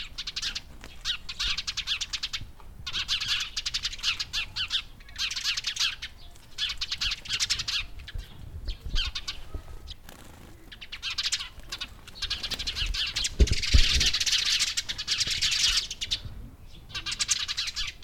새소리.mp3